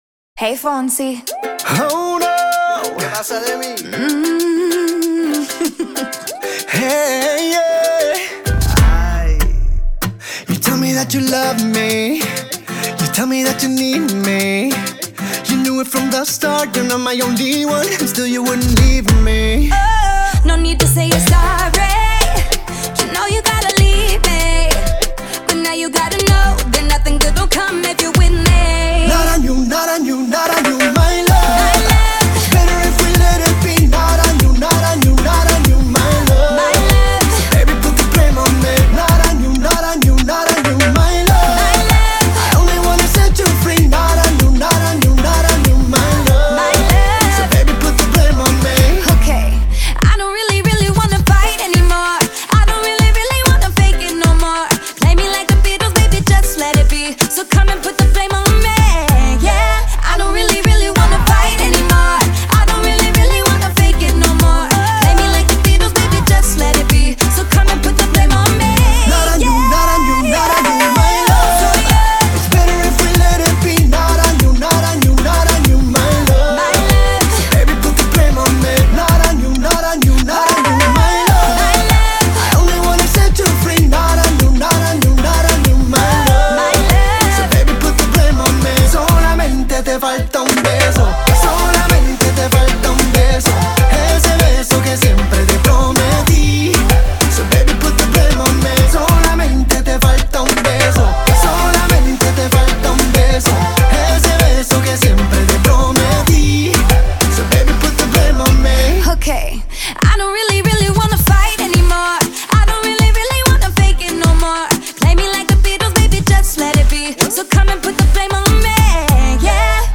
зажигательный поп-латин трек
который сочетает в себе элементы реггетона и поп-музыки.